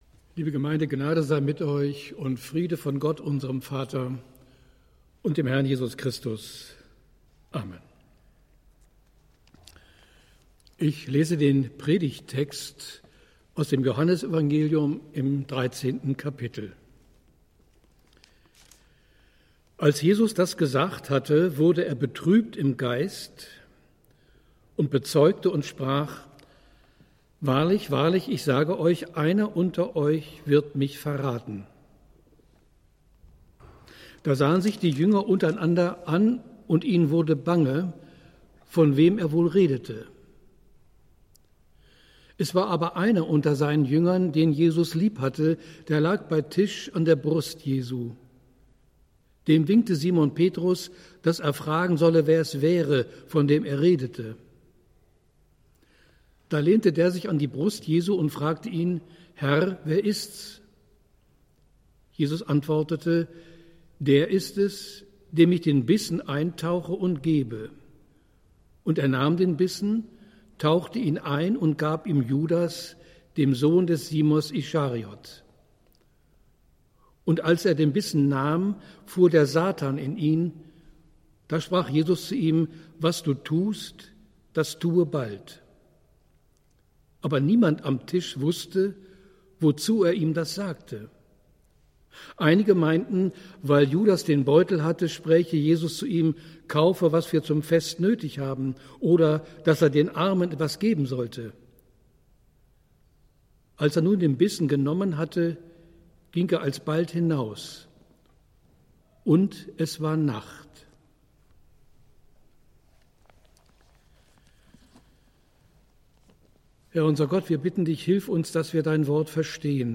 Predigt des Gottesdienstes aus der Zionskirche vom Sonntag, 21.02.2021